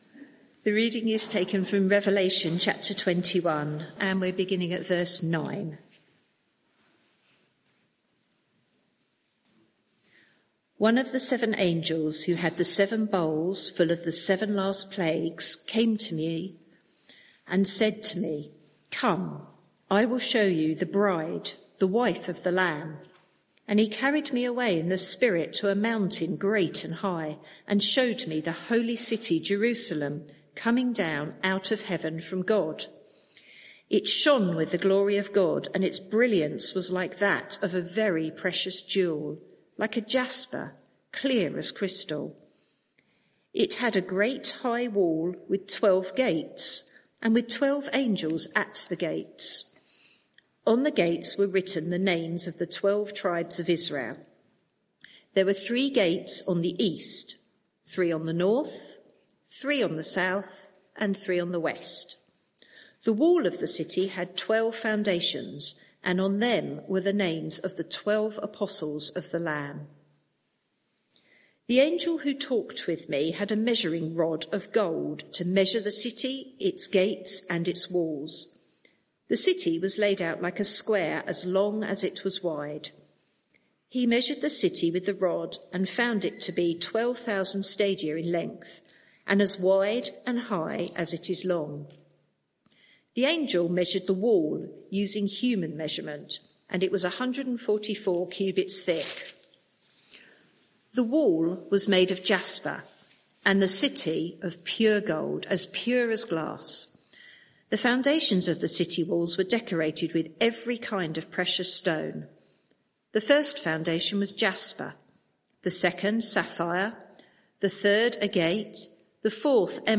Media for 6:30pm Service on Sun 04th May 2025 18:30 Speaker
Sermon (audio) Search the media library There are recordings here going back several years.